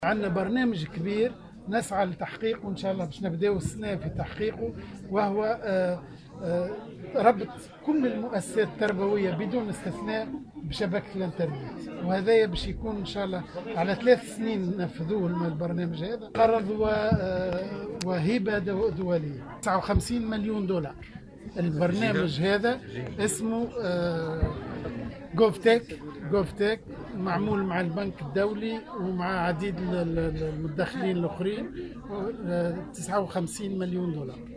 وأضاف في تصريح اليوم لمراسلة "الجوهرة أف أم" على هامش إشرافه على افتتاح مخبر للاعلامية بالمدرسة الإعدادية الحنايا بمدينة زغوان، أن القيمة الجملية للمشروع الذي سيتم إنجازه بالشراكة مع البنك الدولي وعديد المتدخلين الآخرين، تبلغ 59 مليون دولار، وهي اعتمادات ستكون في شكل قرض وهبة دولية.